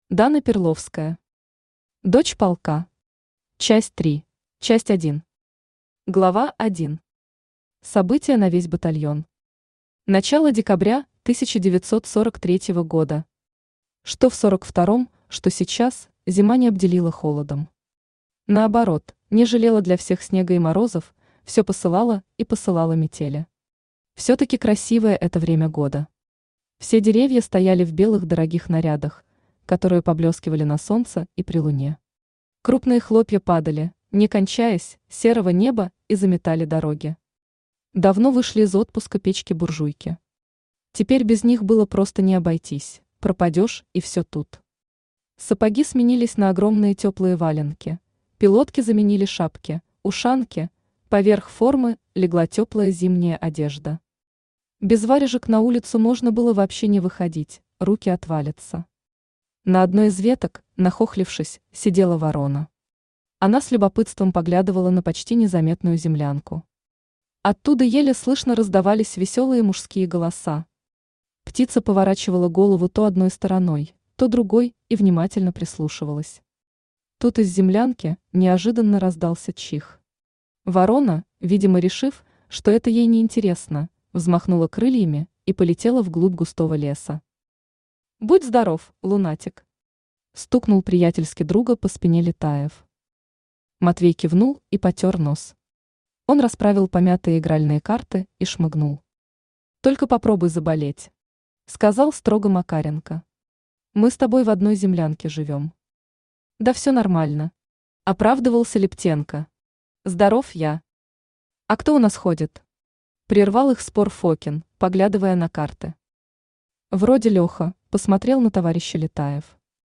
Аудиокнига Дочь полка. Часть 3 | Библиотека аудиокниг